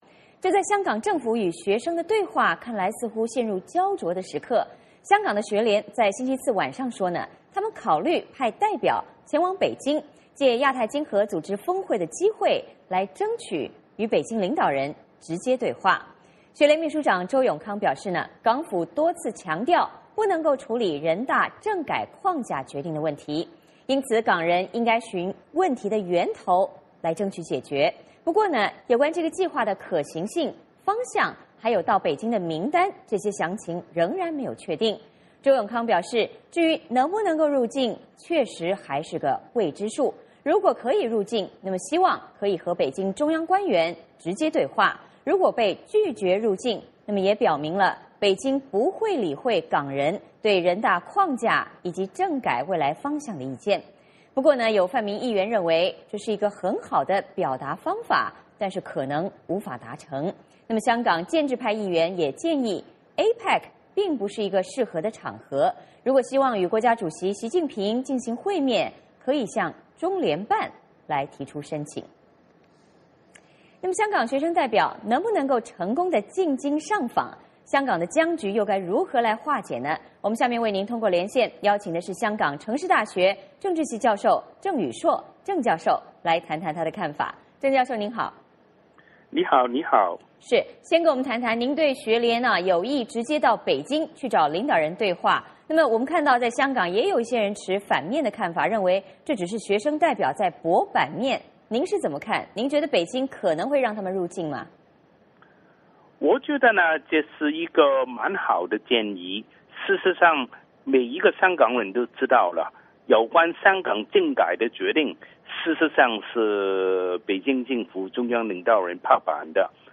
VOA连线：香港学联拟赴北京与领导人对话